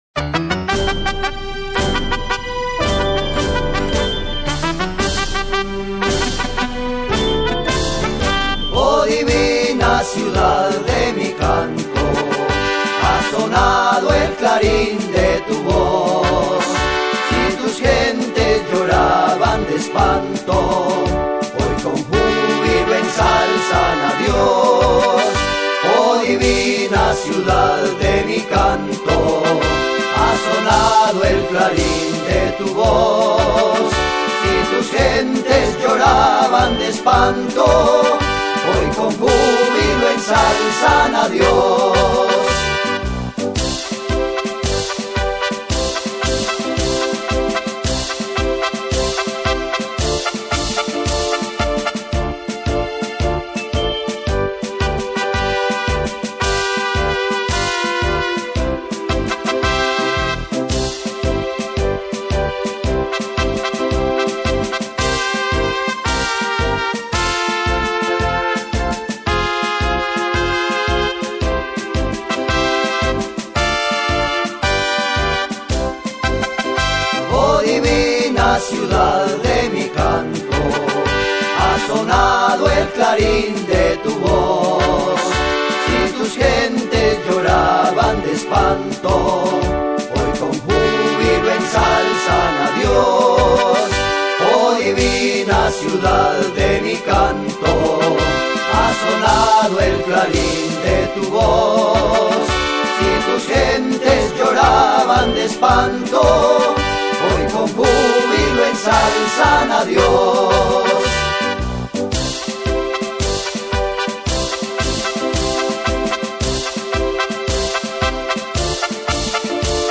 10-pista himno a toro.mp3